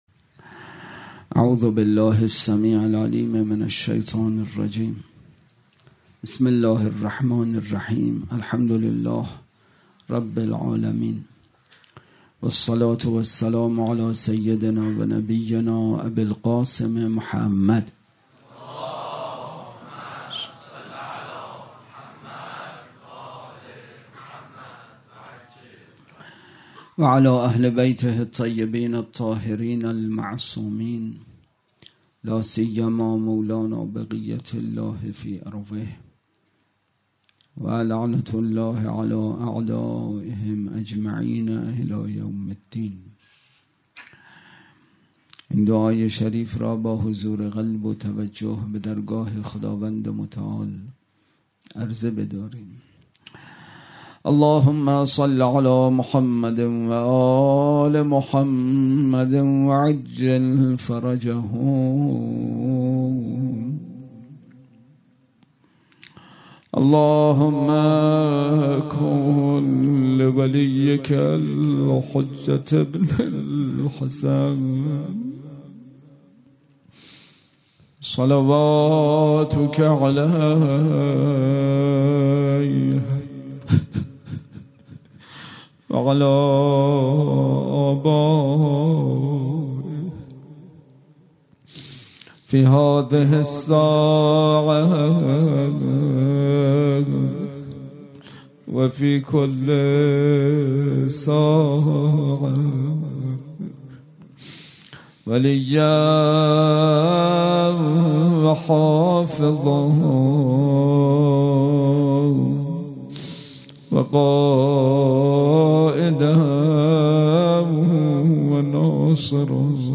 سخنرانی
برگزارکننده: دبیرستان علوی